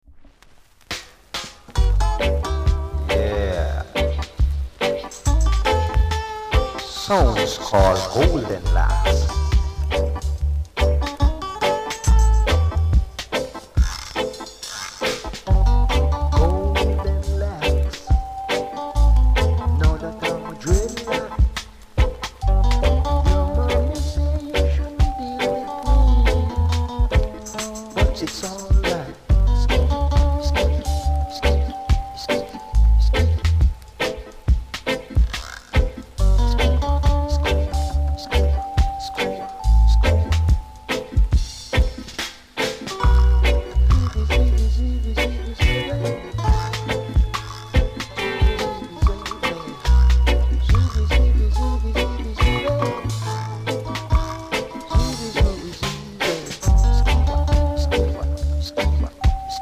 ※全体的に薄くプレスノイズがあります。ほか小さなチリノイズが少しあります。